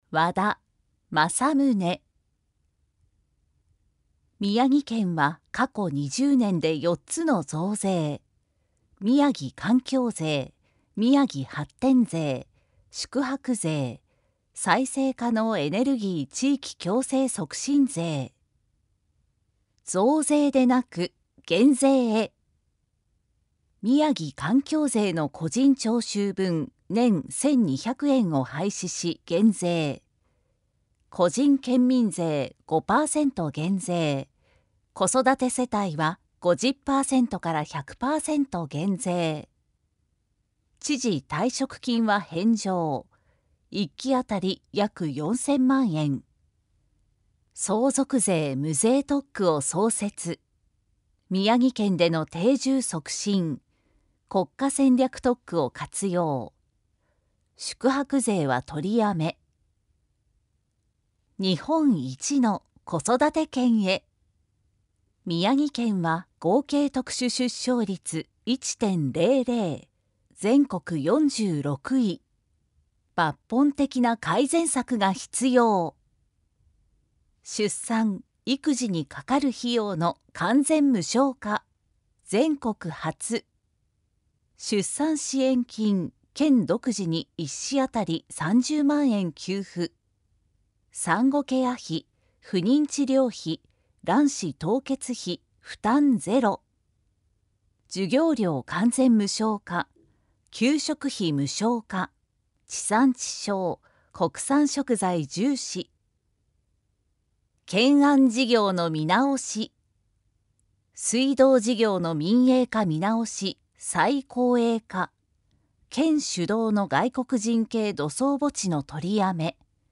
宮城県知事選挙候補者情報（選挙公報）（音声読み上げ用）